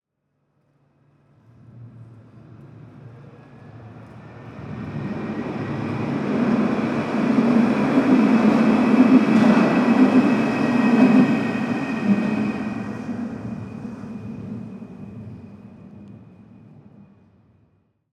Tram passing #3
A modern Parisian tram passes by.
UCS Category: Trains / Tram (TRNTram)
Type: Soundscape
Channels: Stereo
Disposition: ORTF
Conditions: Outdoor
Realism: Realistic
Equipment: SoundDevices MixPre-3 + Neumann KM184